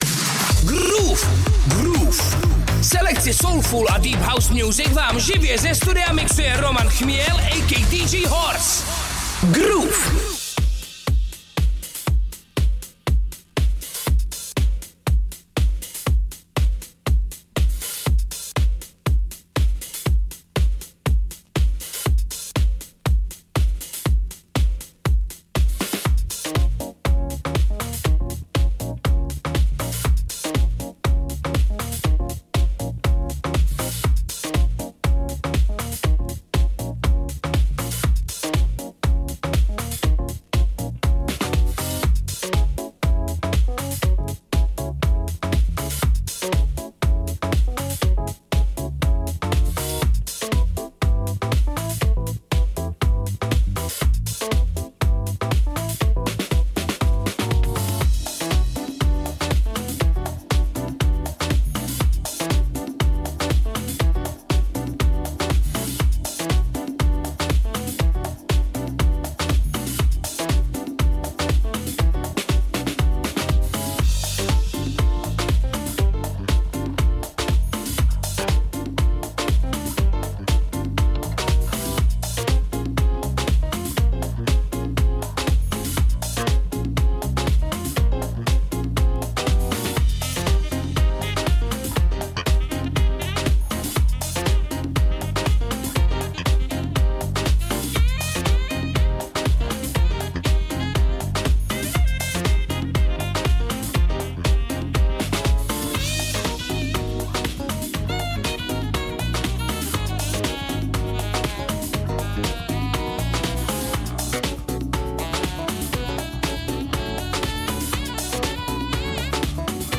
vinyl set